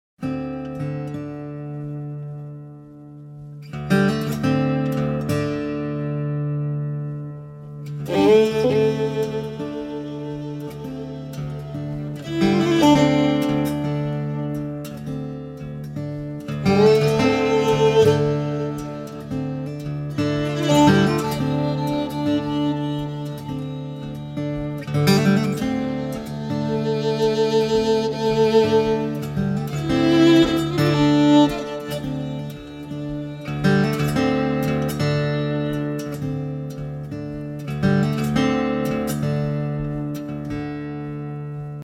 We wanted to accommodate tranquillity and improvisation.
Recorded and mixed on analogue tape
Acoustic guitar & dobro
slide guitar, violin,
bouzouki & mandolin